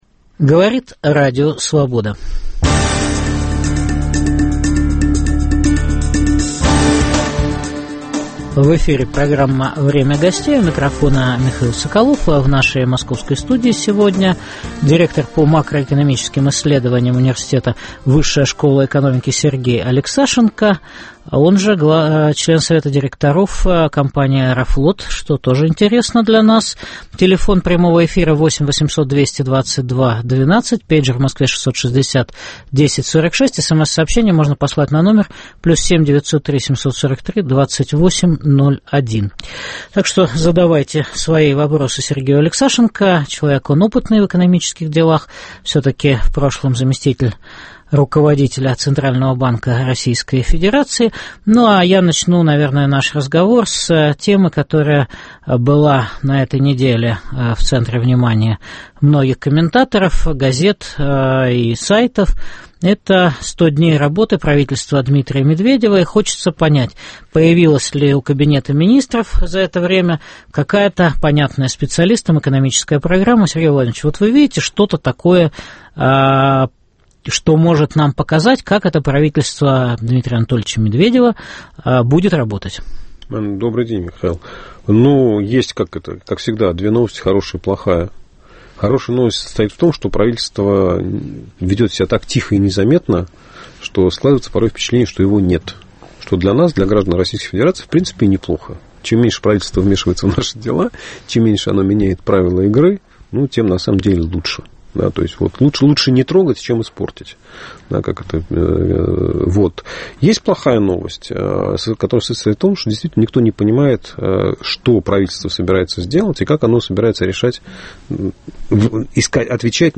Появилась ли у правительства Дмитрия Медведева за 100 дней работы экономическая программа? В студии Радио Свобода выступит директор по макроэкономическим исследованиям Высшей школы экономики Сергей Алексашенко.